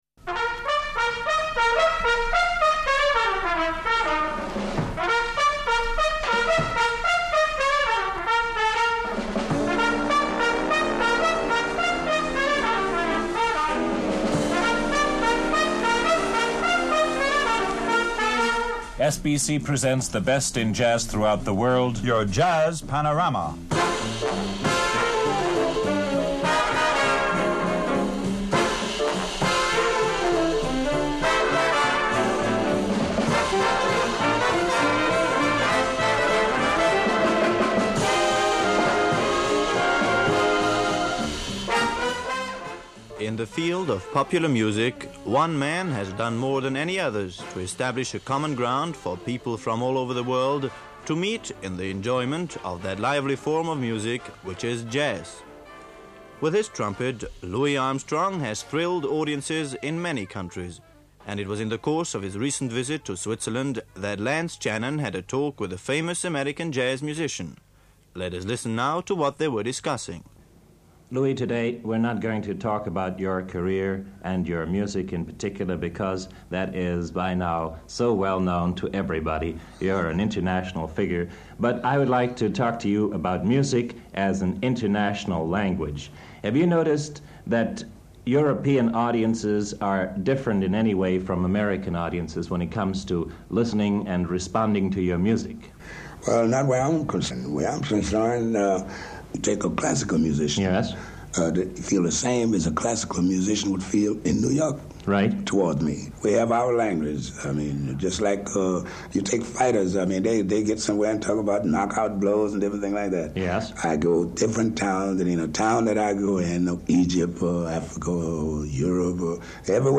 Jazz legend Louis Armstrong talks to SRI in 1955.